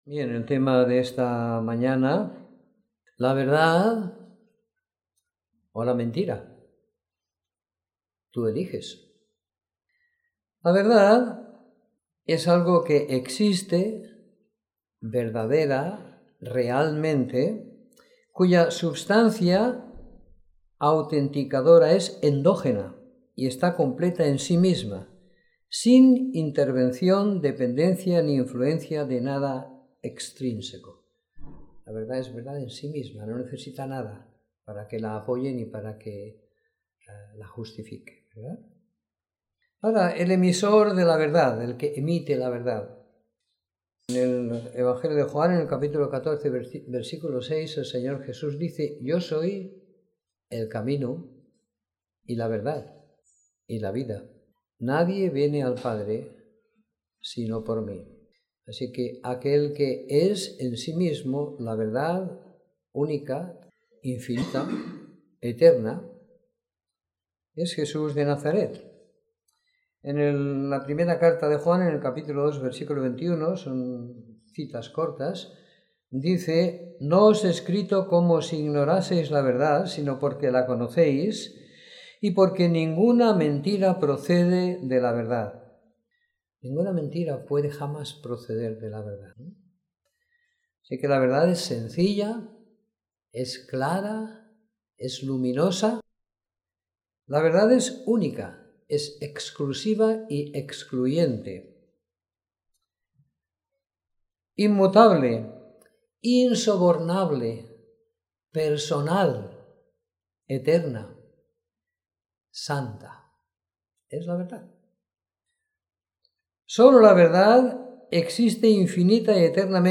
Reunión semanal de Predicación del Evangelio
Domingo por la Mañana . 24 de Julio de 2016